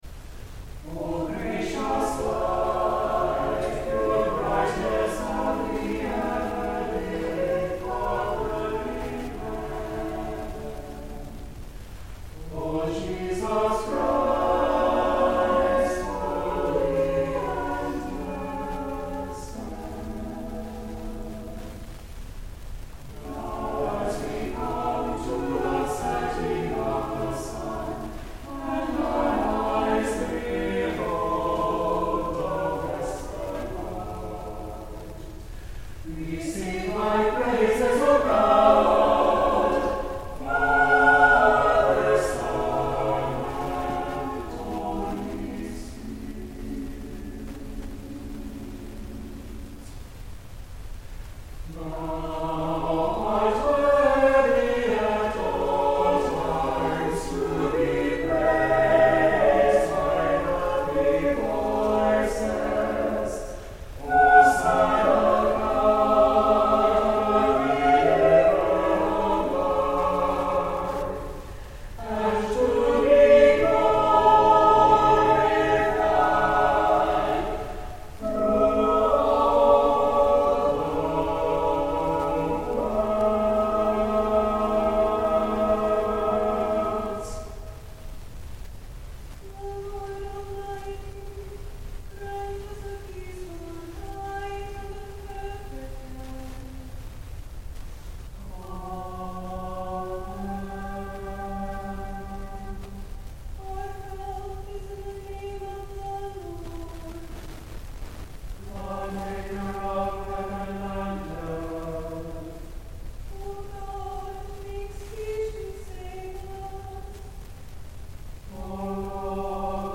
Worship and Sermons from Christ Episcopal Church in Little Rock, Arkansas
On Sundays, virtual and in-person services of prayers, scripture, and a sermon are at 8 a.m., 10:30 a.m., and 6 p.m., and a sung service of Compline begins at 7 p.m.